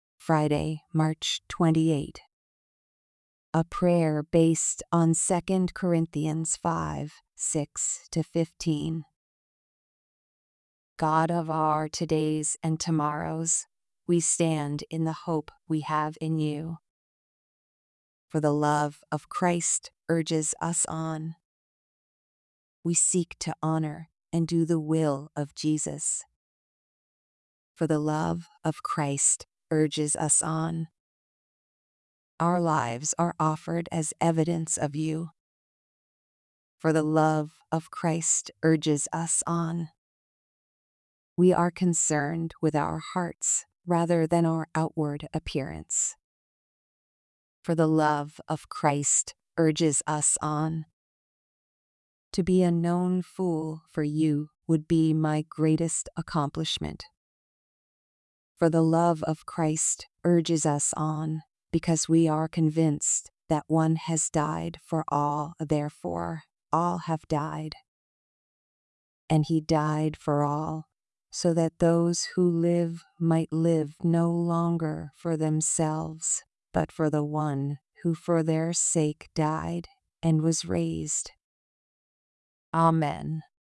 基于哥林多后书5:6-15的祷告